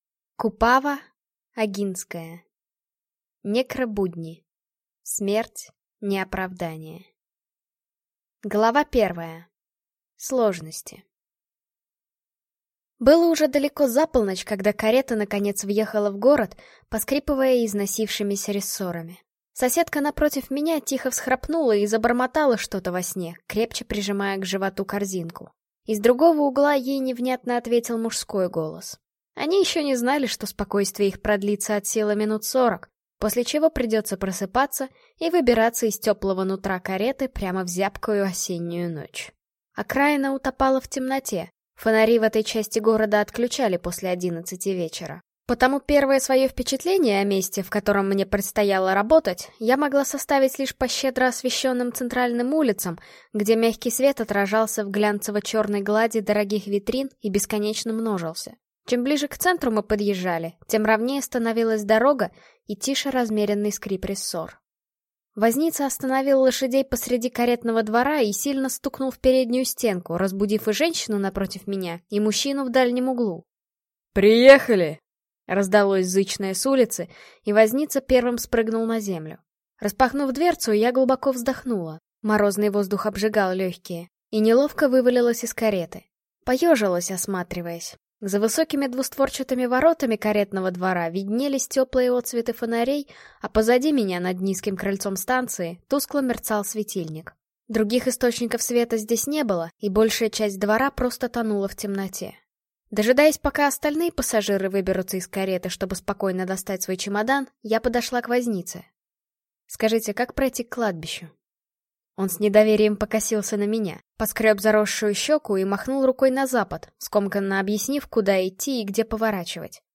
Аудиокнига Некробудни. Смерть – не оправдание | Библиотека аудиокниг